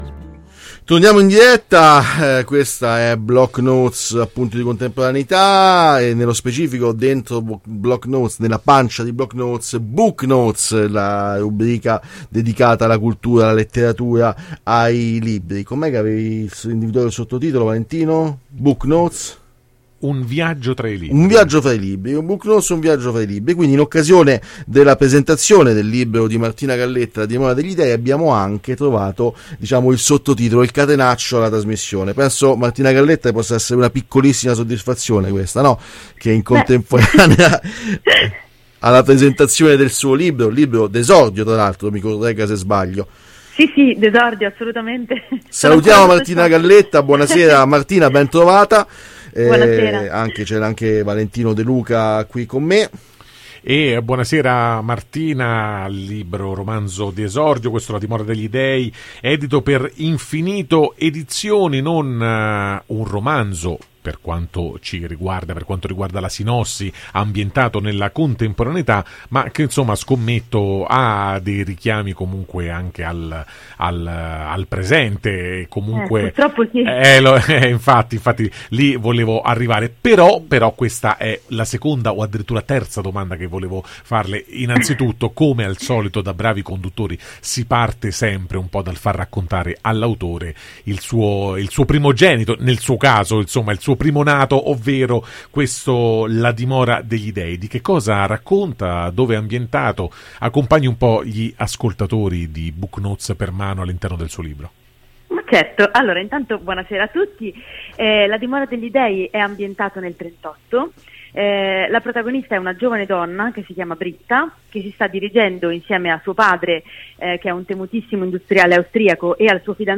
Puntata all’insegna dei debutti quella di giovedì sera a Radio Città Aperta.